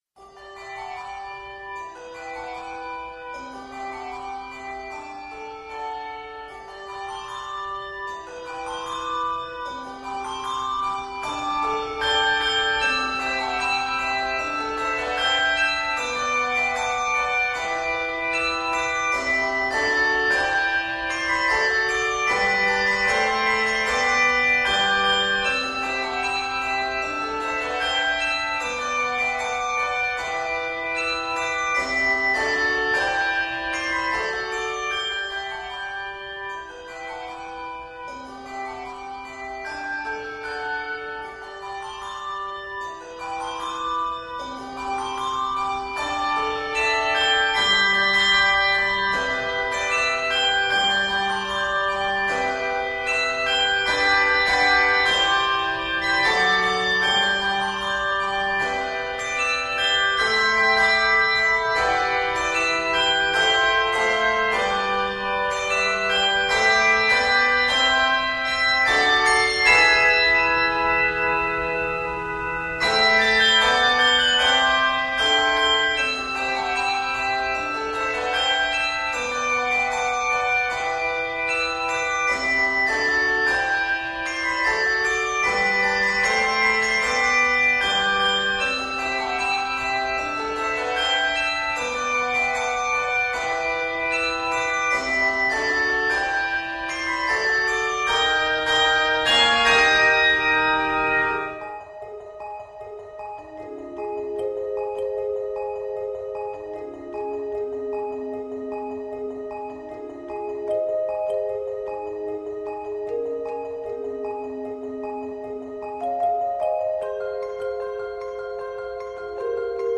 It is scored in G Major and Ab Major.